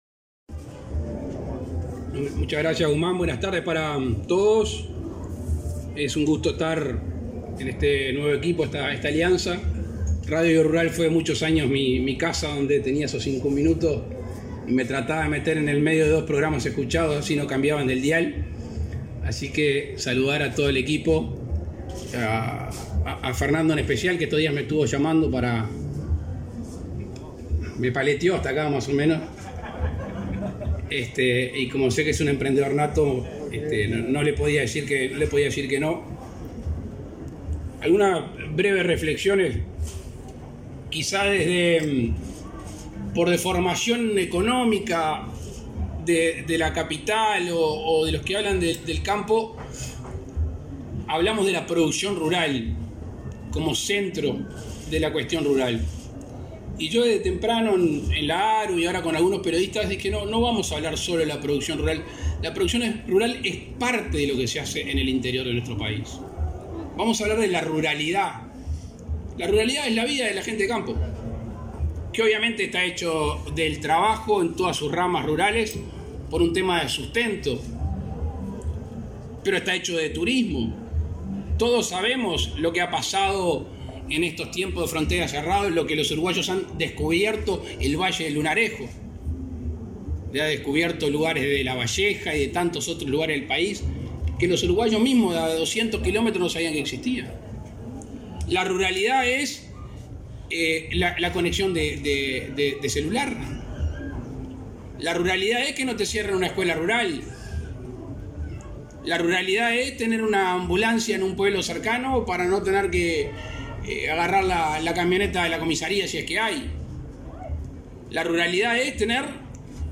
Palabras del presidente de la República, Luis Lacalle Pou
En ocasión del lanzamiento del canal Campo Rural TV, en la Expo Prado 2021, este 15 de setiembre, el mandatario destacó la importancia de que un medio